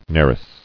[nar·is]